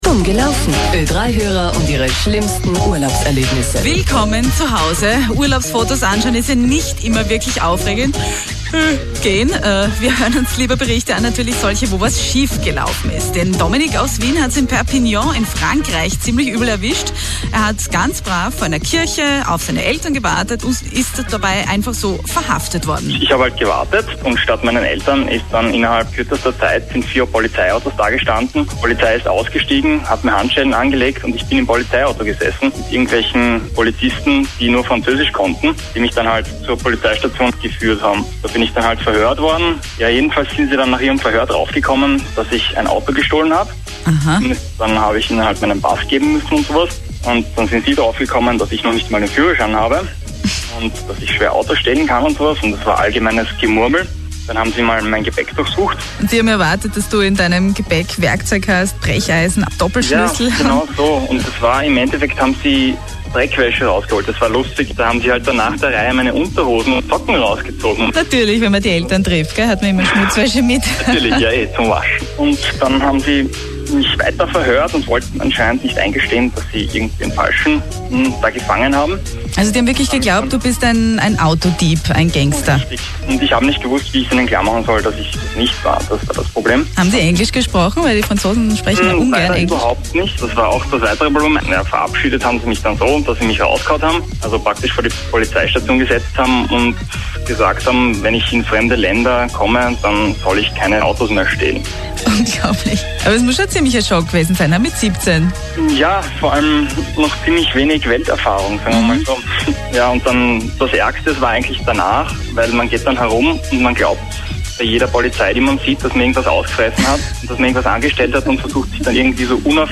Well, it’s been a while (actually Summer 1999) since I was stopped by the police in france: I was put on handcuffs and questioned – They thought that I’ve stolen a car… Well now OE3 (The national radio station here in Austria) thought, that this story was cool, they phoned me and this is the result on-air: (German, mp3) Festnahme/MP3